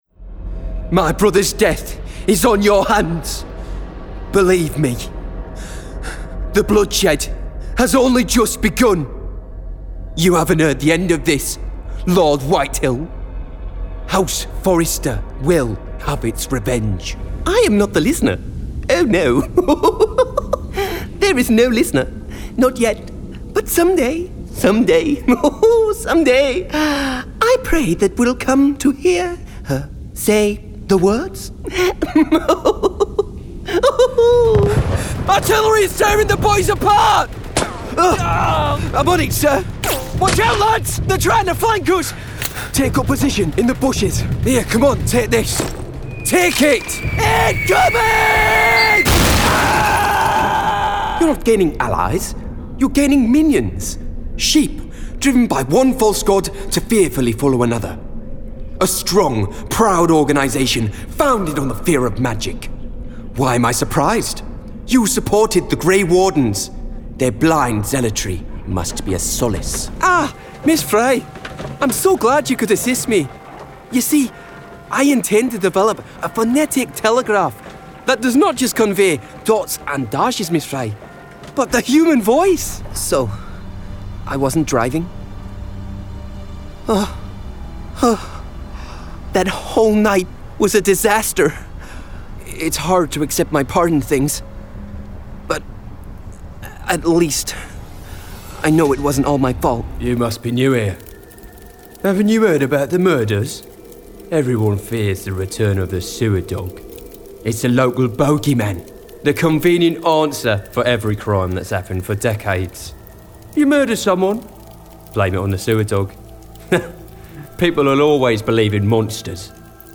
Video Game Reel
• Native Accent: Teeside
• Home Studio
His native north-east is light and amicable, but he can plunge those vocal depths for villainy at a finger click.